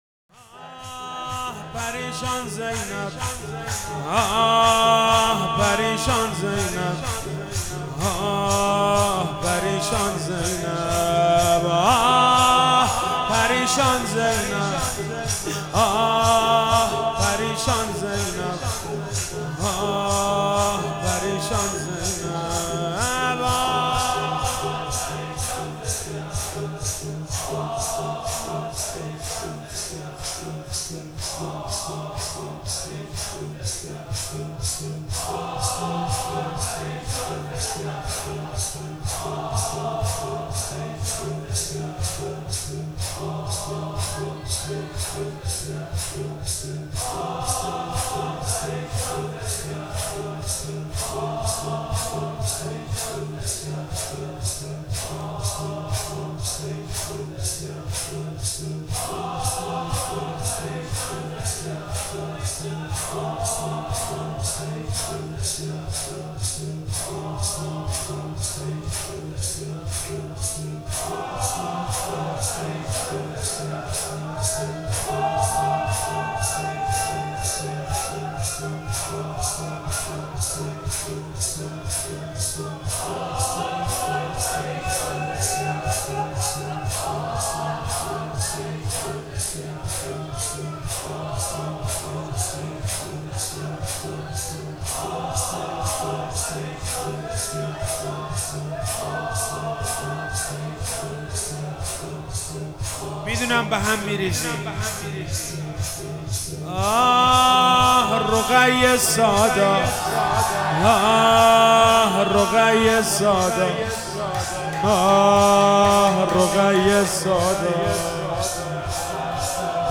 محرم 98 شب دوم - شور - آه پریشان زینب